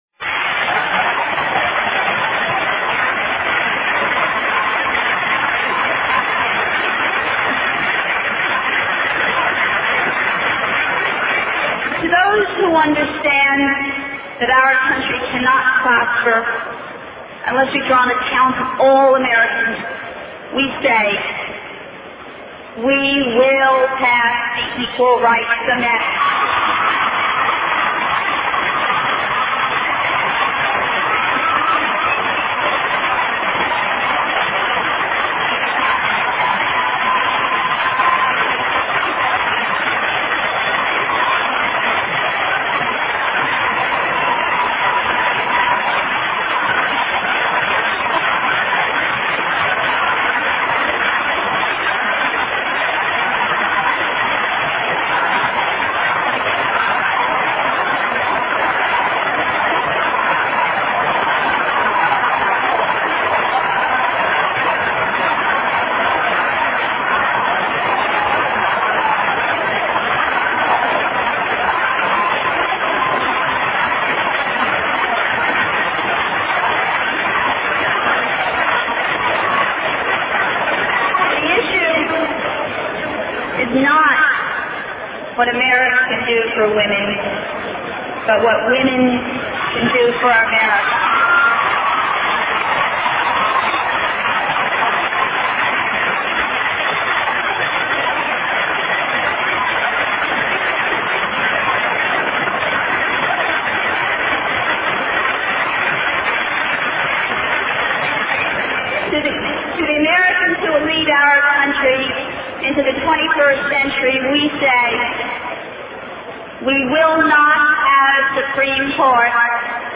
经典名人英语演讲(中英对照):Vice-Presidential Nomination Acceptance Speech 6